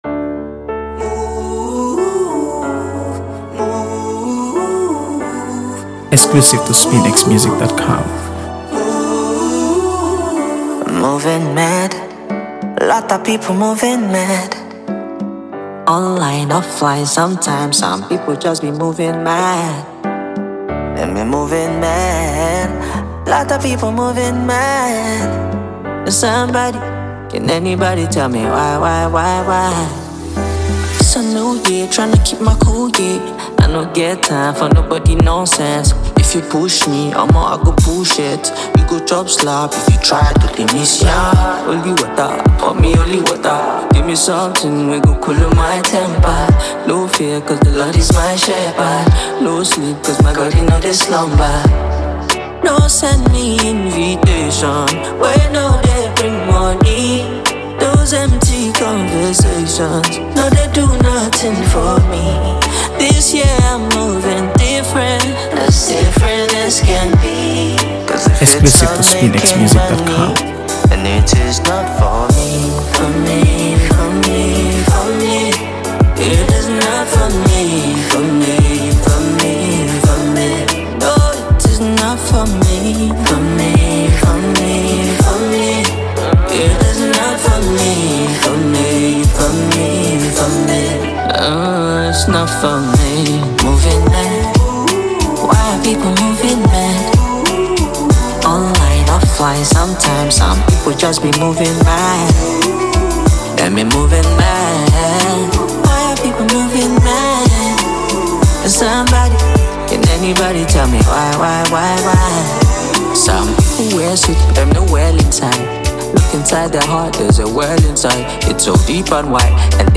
AfroBeats | AfroBeats songs
soulful melodies and heartfelt lyrics